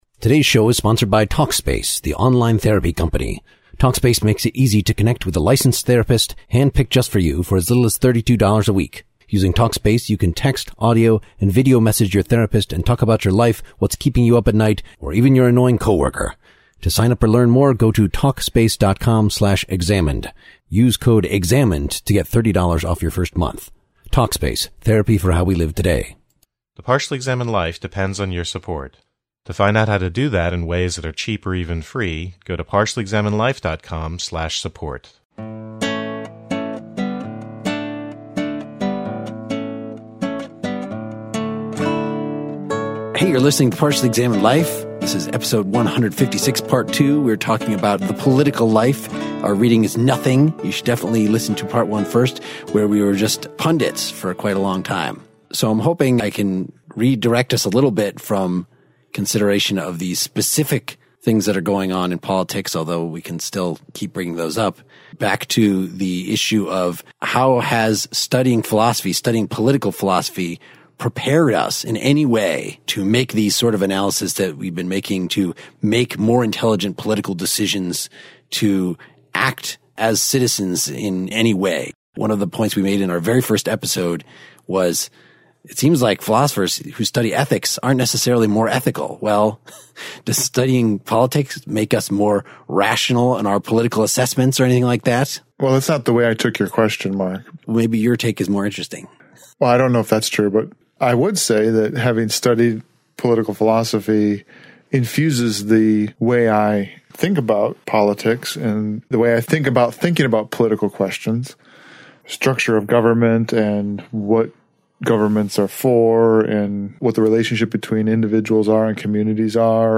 Philosophy and Politics Free-Form Discussion (Part Two)
Continuing our liberal bubble-bursting exercise, the core foursome address more directly the question of how philosophy is supposed to shape one's political views and actions. Is there a non-partisan way of describing ""the public good"" that we can use as a touchstone to communicate with and maybe convince political opponents? What's a philosophically responsible way to use political rhetoric in the face of an apathetic and/or ignorant public?